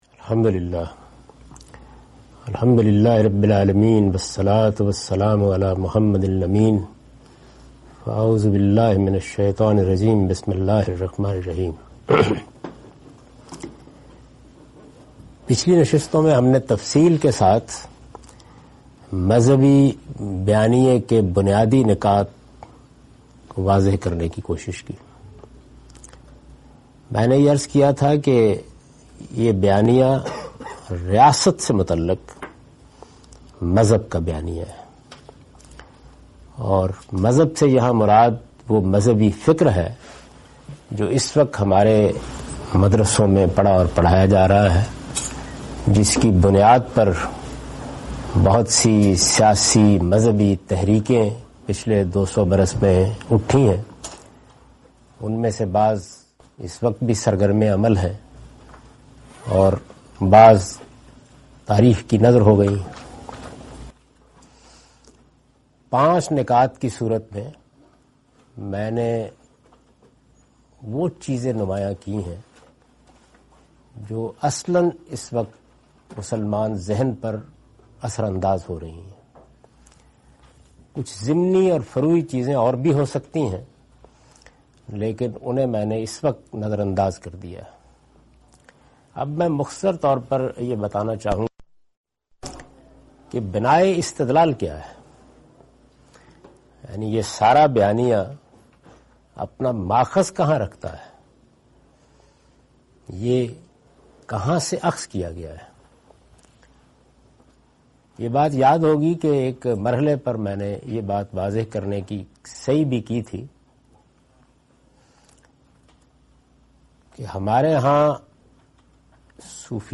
In this video, he presents the "Traditional Narrative" of Islam and discusses the "Summary of Argument". This lecture was recorded on 10th June 2015 in Dallas (USA)